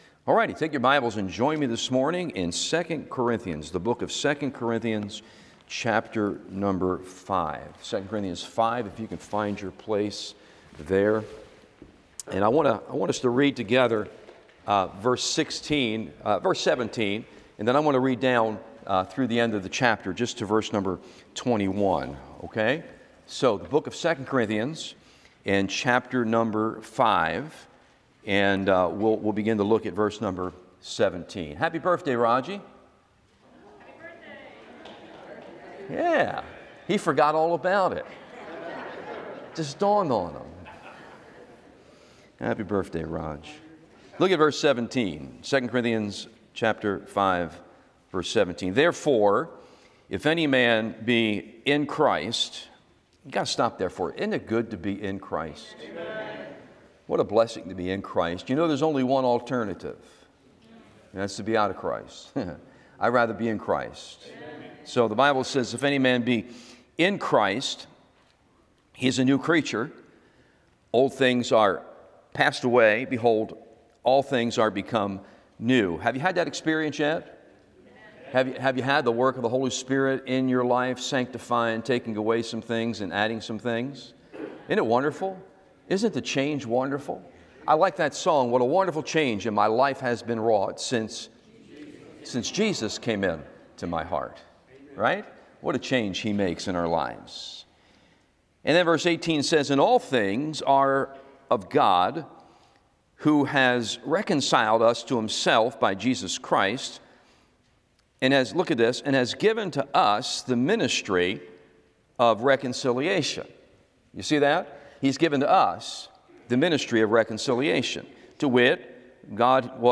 Always Abounding Series Sunday AM Service